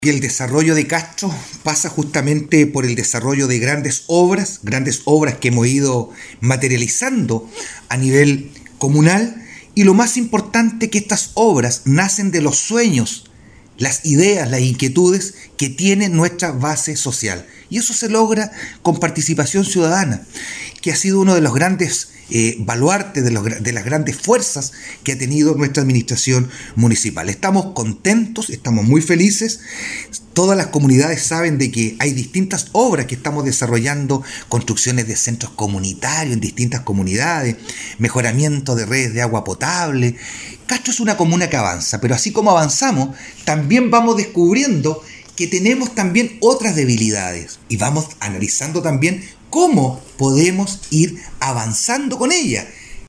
ALCALDE-VERA-DESARROLLO-DE-CASTRO.mp3